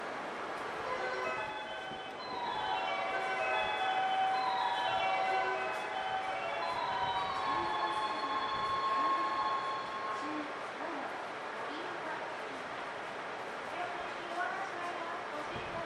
○発車メロディー○
スピーカーはNational横長型が使用されています。音質は普通で、音量は大きいです。
鳴りやすさですが基本的にフルコーラスがメジャーですね。
１２番線■東北新幹線